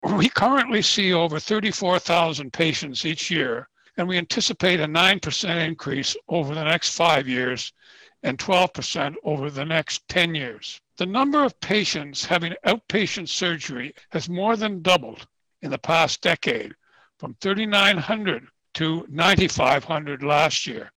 a presentation to Quinte West Council on Monday night